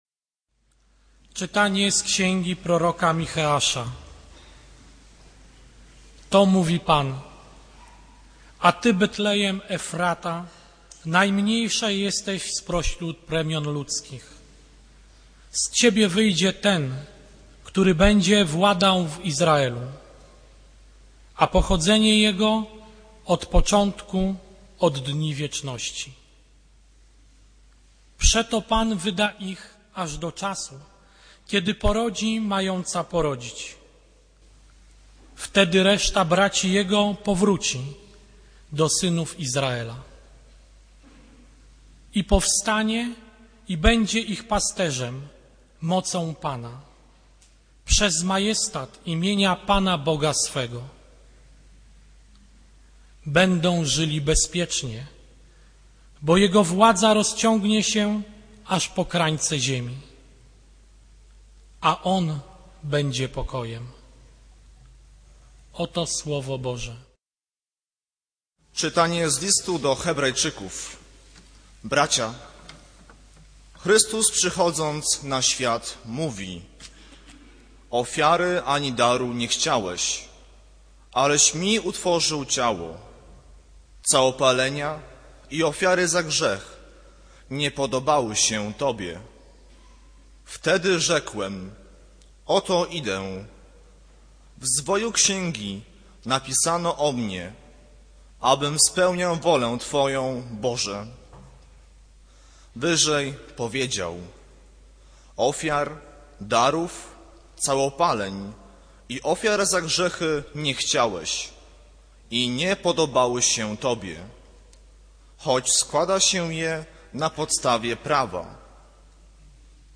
Kazanie z 20 stycznia 2008r.